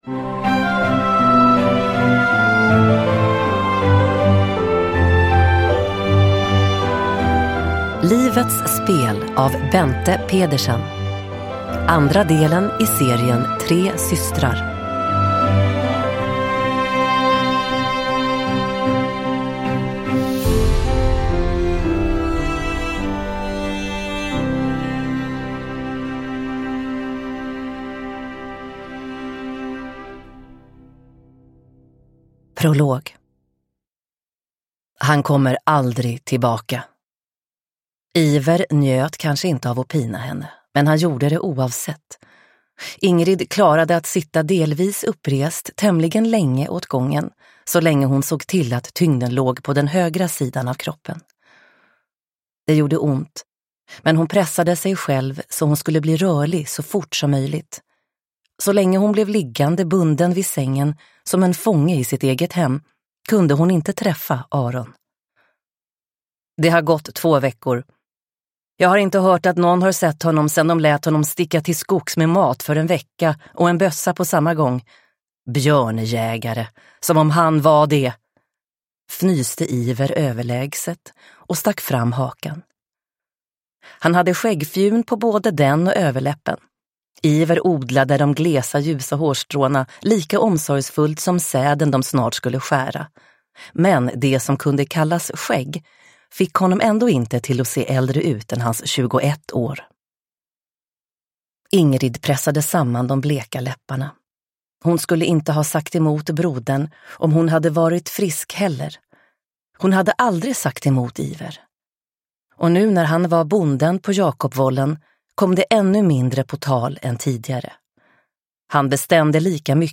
Livets spel – Ljudbok – Laddas ner